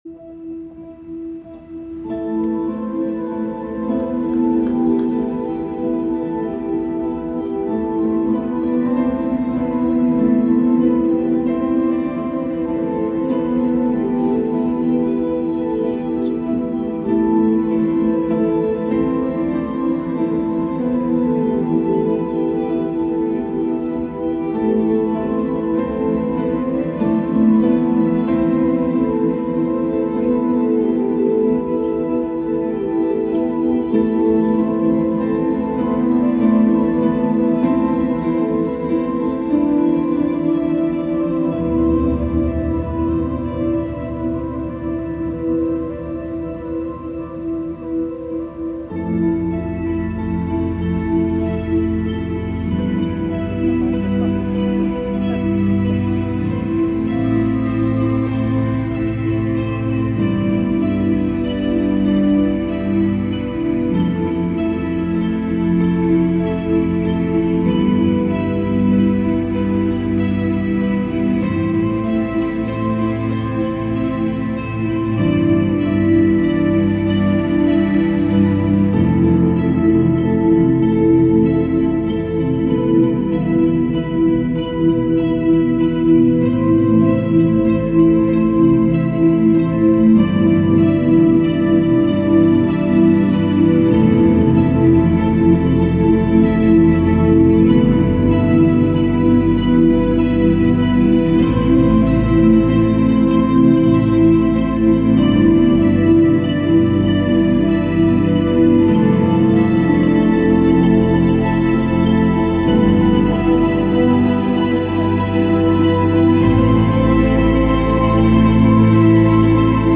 the high singing bit
Chicago, 9/22/24